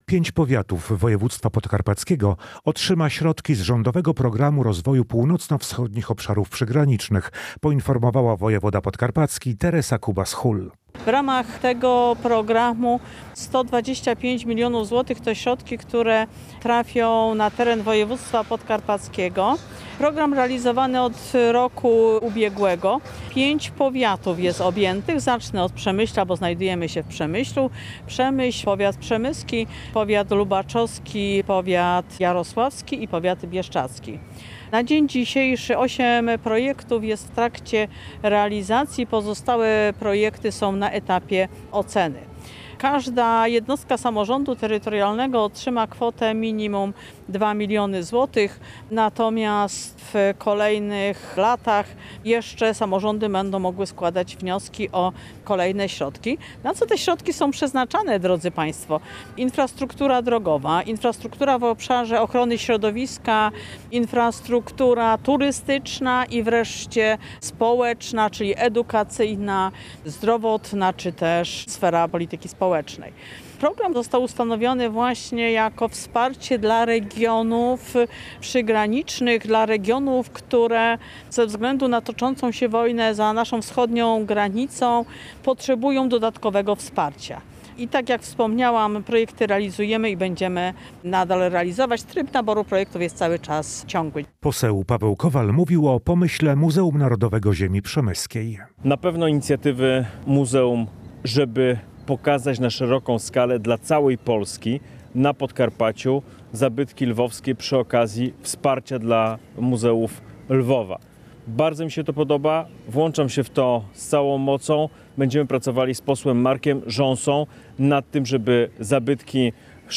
Każdy z samorządów otrzyma co najmniej 2 miliony złotych – poinformowała wojewoda podkarpacki Teresa Kubas – Hul, podczas konferencji w Przemyślu. Prawo do ubiegania się o pieniądze mają powiaty: przemyski, lubaczowski, jarosławski, bieszczadzki oraz miasto Przemyśl.